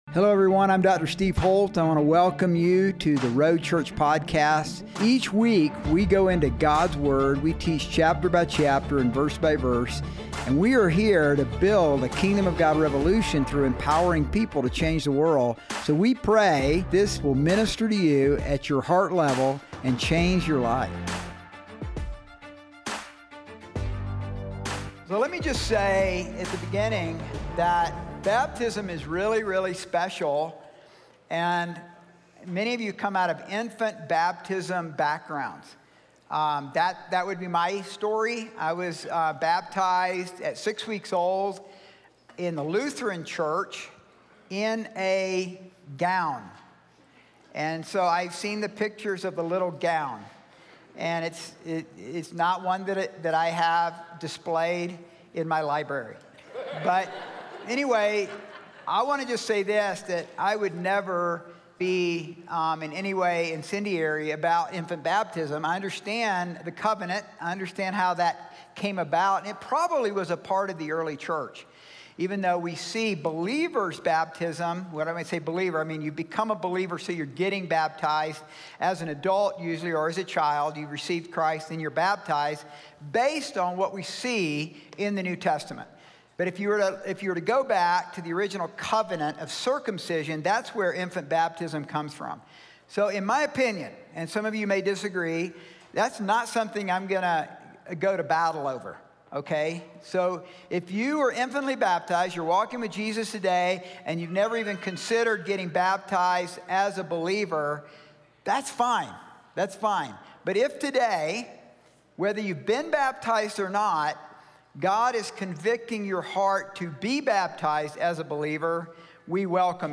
Sermons | The Road Church